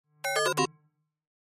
zumbido.mp3